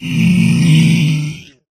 zpigdeath.ogg